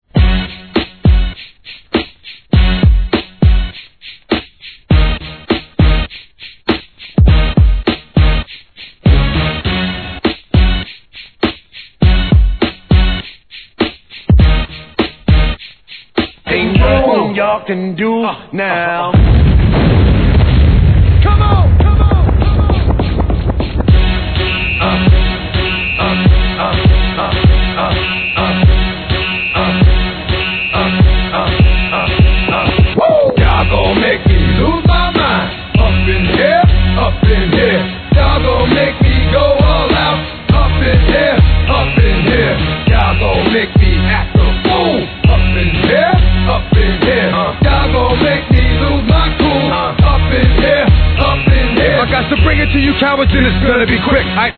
HIP HOP/R&B
年代を問わず往年の名曲を集め、DJのために繋ぎ易さも考慮されたREMIX人気シリーズ57番!!